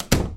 DoorClose.wav